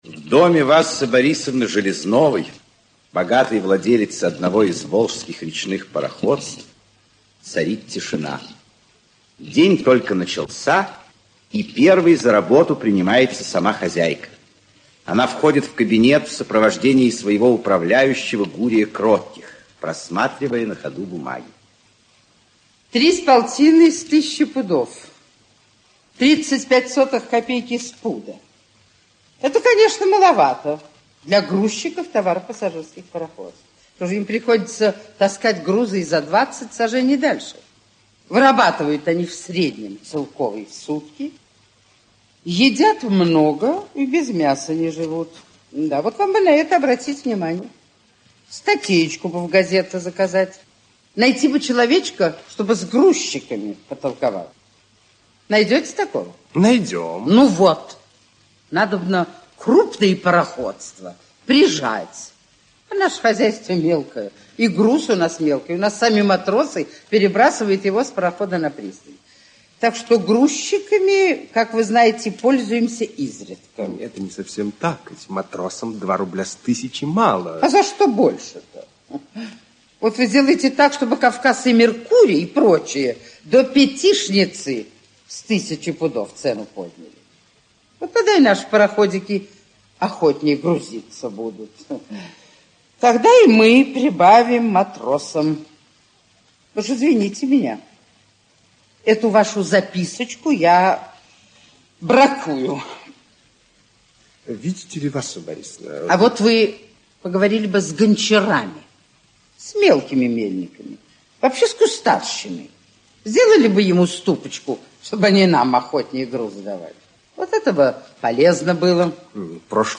Аудиокнига Васса Железнова (спектакль) | Библиотека аудиокниг
Aудиокнига Васса Железнова (спектакль) Автор Максим Горький Читает аудиокнигу Актерский коллектив.